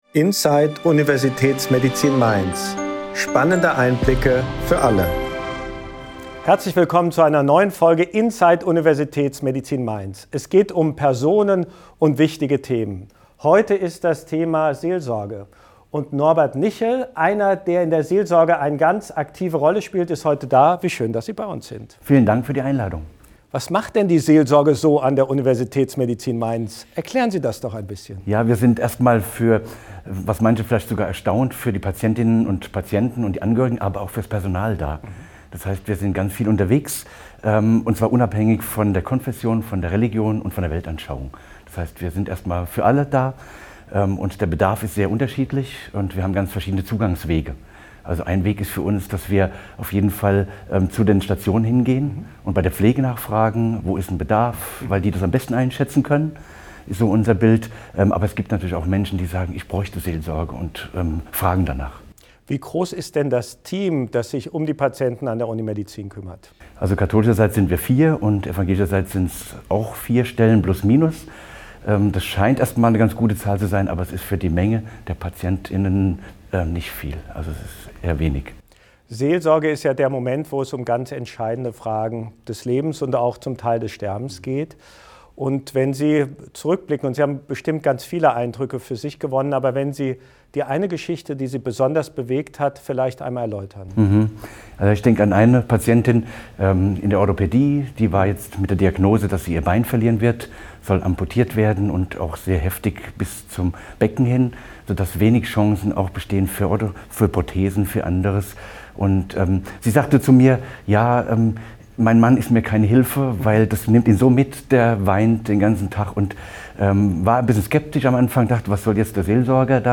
eines bewegenden Gesprächs